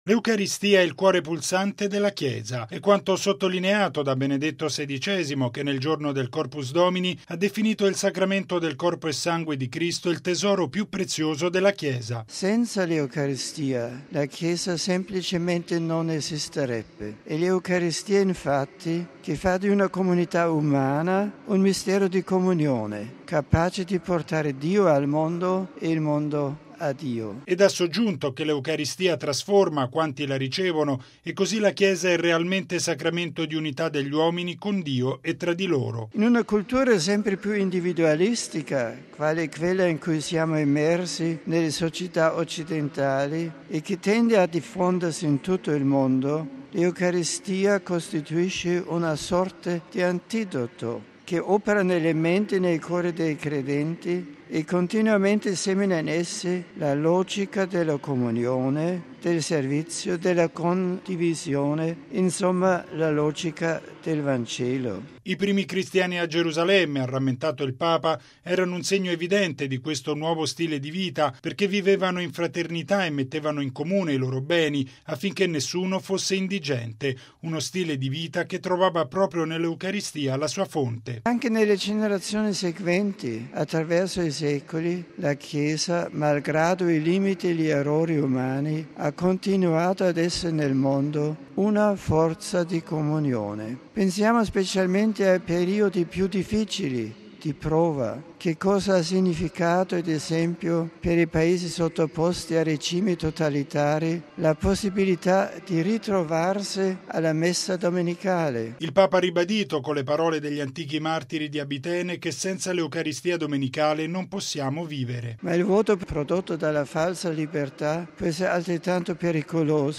◊   All’Angelus in piazza San Pietro, Benedetto XVI ha svolto, stamani, un’appassionata riflessione sul valore inestimabile dell’Eucaristia.